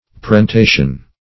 Search Result for " parentation" : The Collaborative International Dictionary of English v.0.48: Parentation \Par`en*ta"tion\, n. [L. parentatio, fr. parentare to offer a solemn sacrifice in honor of deceased parents.